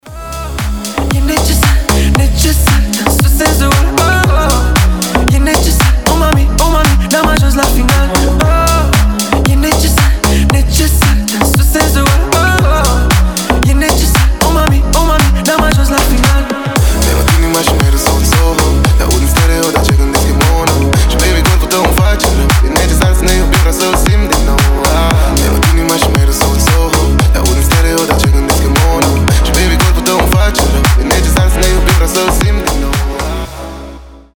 • Качество: 320, Stereo
remix
deep house
Ремикс популярной румынской песни